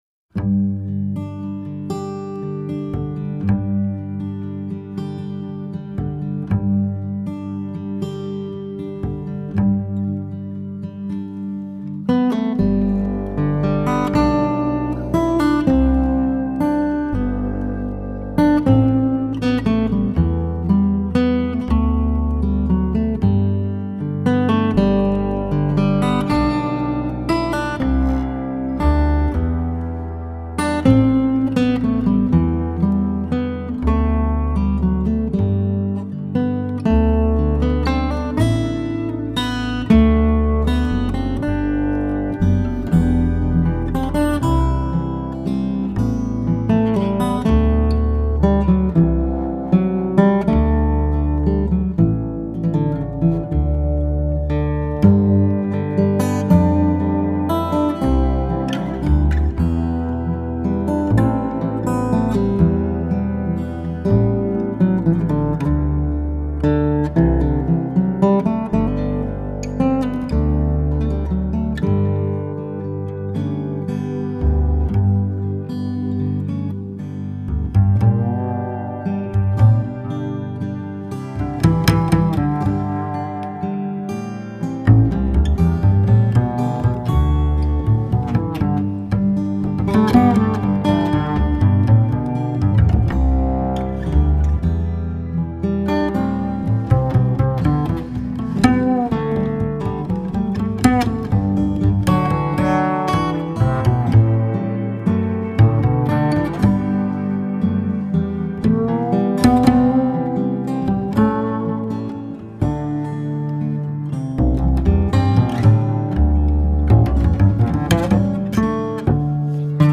[圣诞节专辑]
音乐类型: NewAge